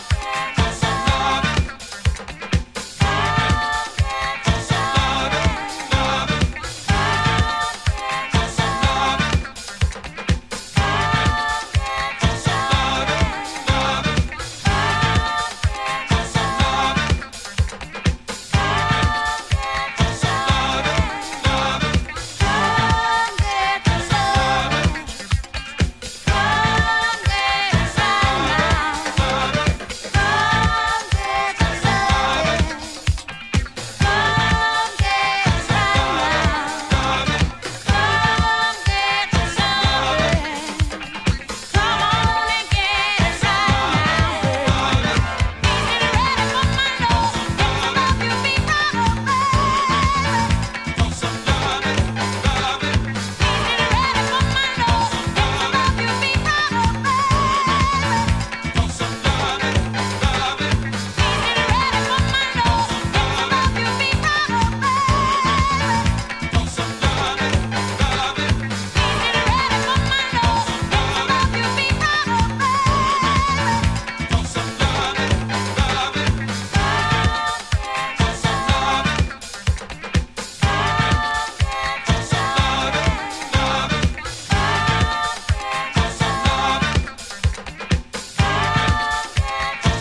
Hot edit
Disco House